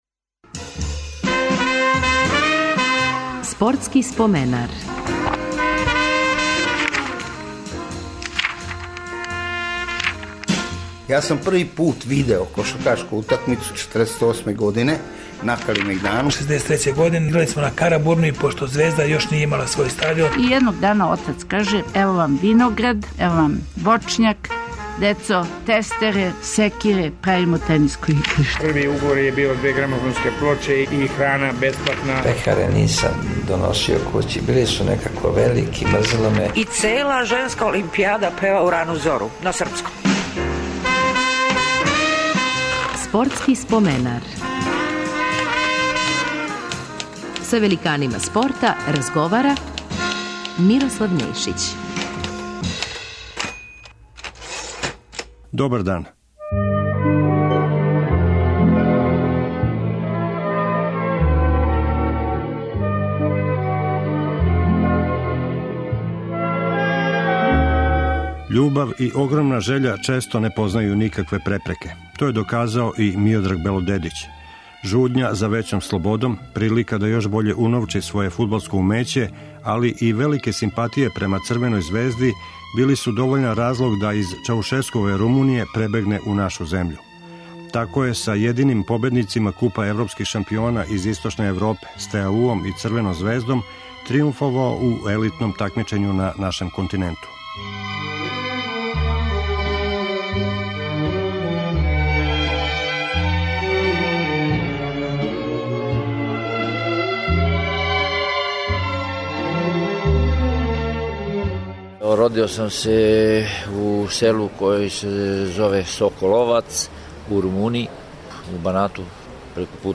Гост емисије биће фудбалер Миодраг Белодедић.